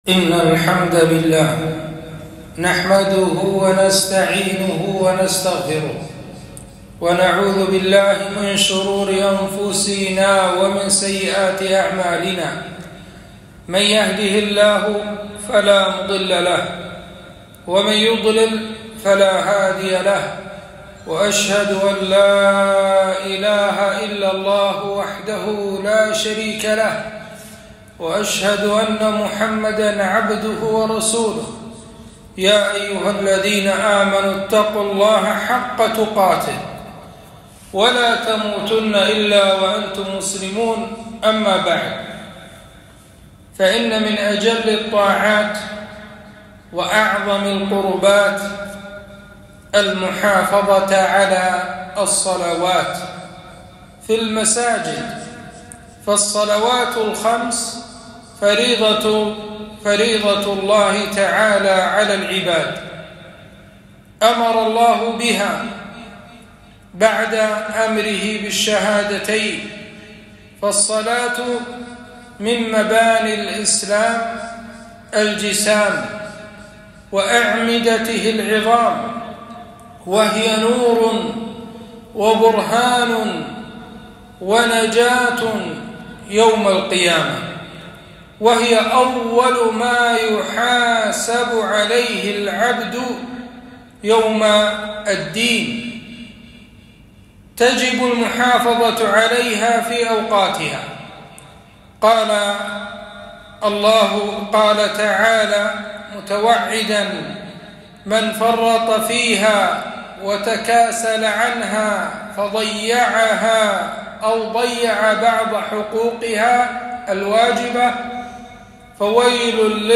خطبة - فضل الصلاة في المسجد